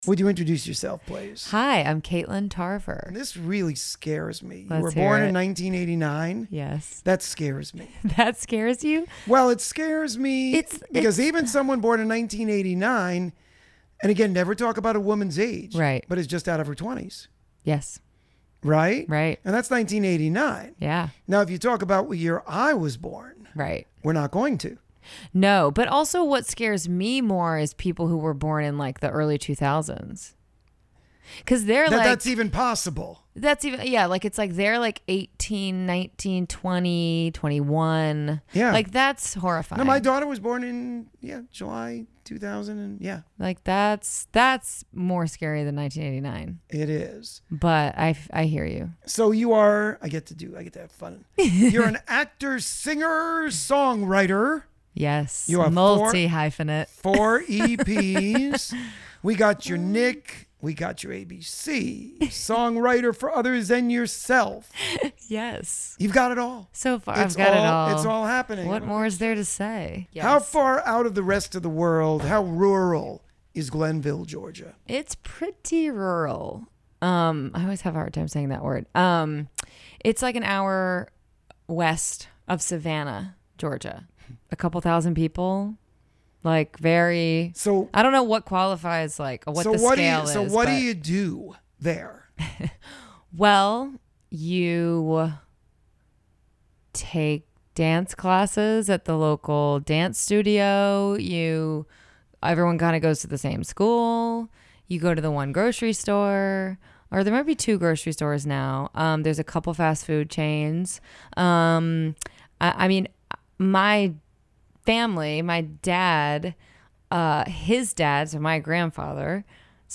This Week's Interview (10/03/2021): Katelyn Tarver